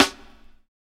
Cardiak Live Snare.wav